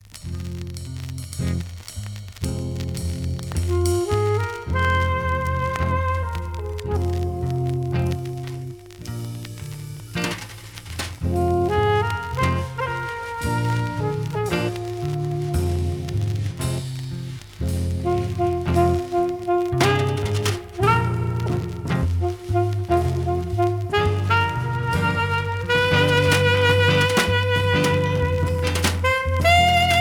Jazz blues instrumental